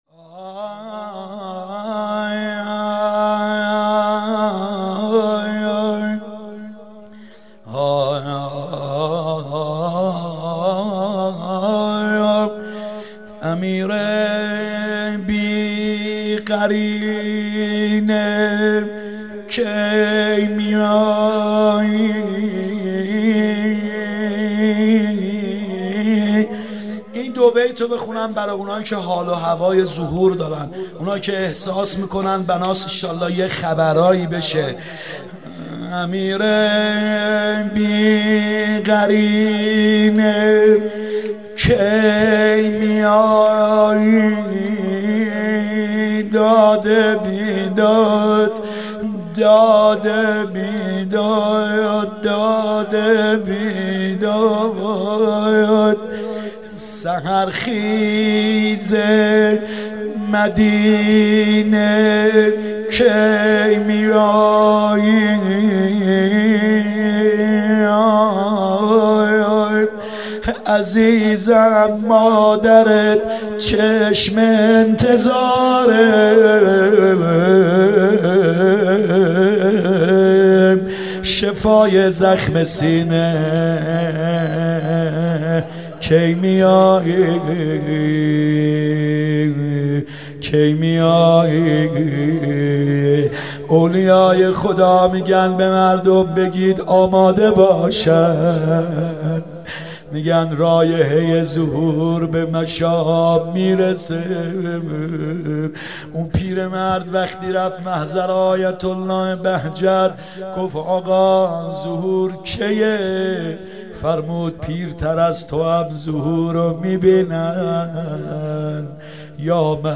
فاطمیه دوم ( شب دوم) هیات یا مهدی (عج ) 1399